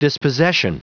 Prononciation du mot dispossession en anglais (fichier audio)
Prononciation du mot : dispossession